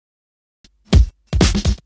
Fill 128 BPM (6).wav